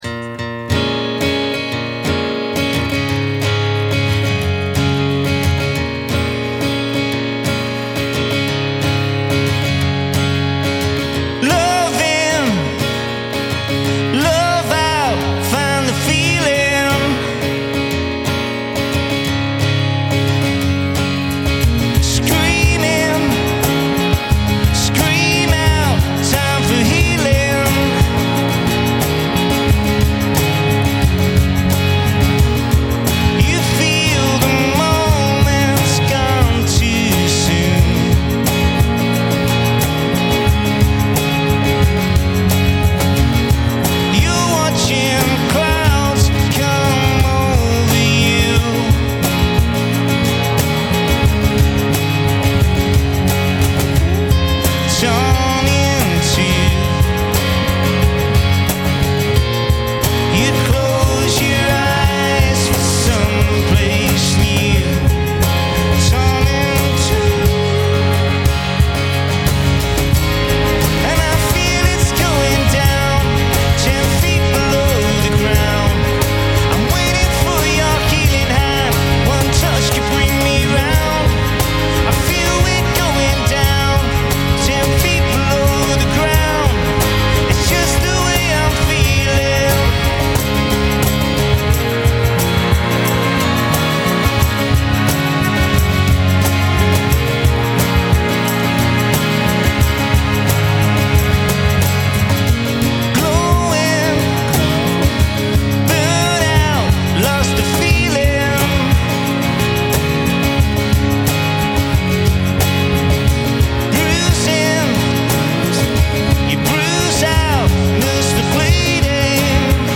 rock bands